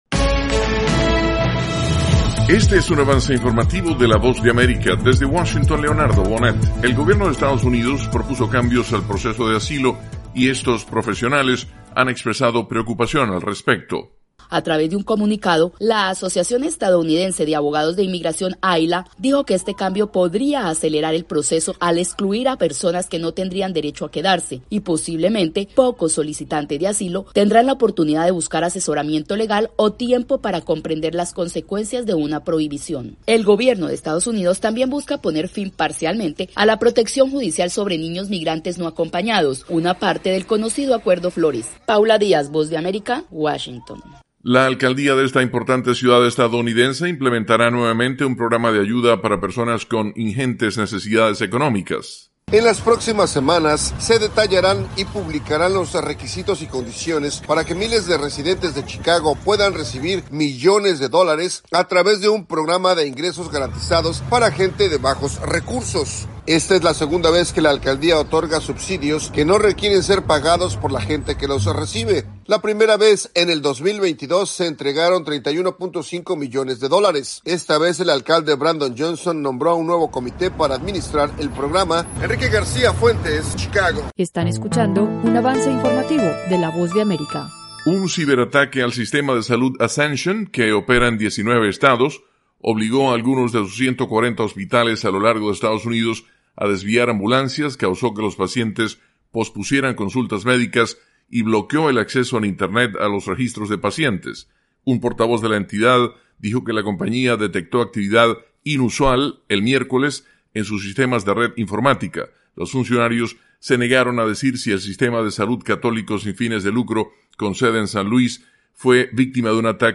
El siguiente es un avance informativo presentado por la Voz de América, desde Washington